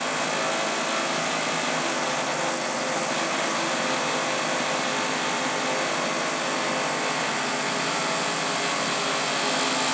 Should I work in this din?